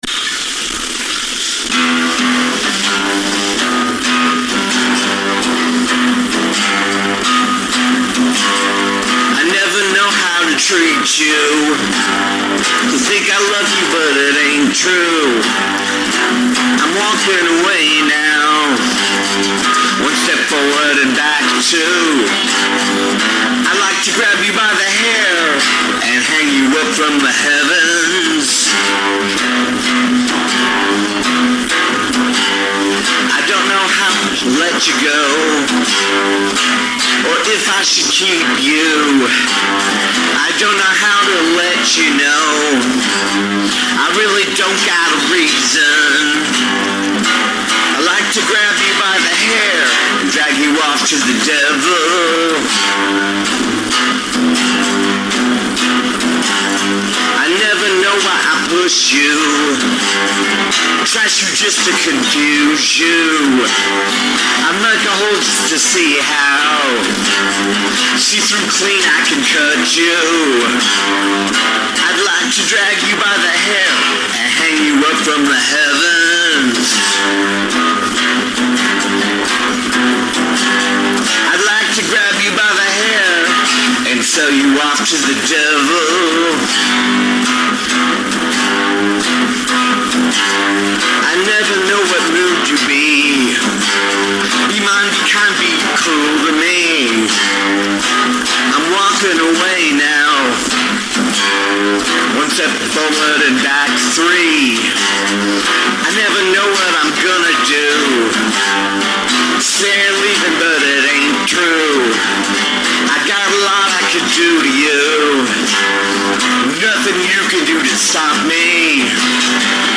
this is not pretty.